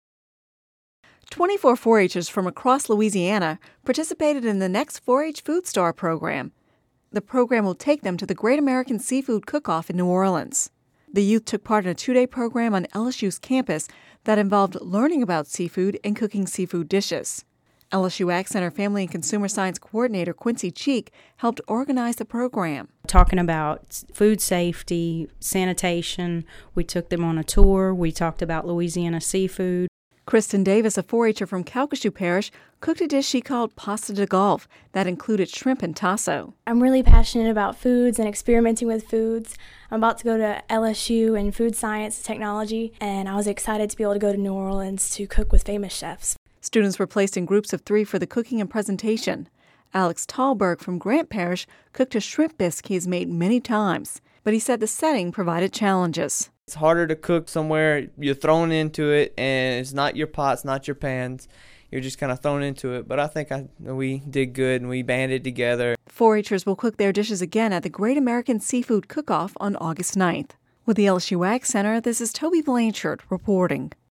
(Radio News 7/26/10) Twenty-four 4-H’ers from across Louisiana participated in the Next 4-H Food Star program.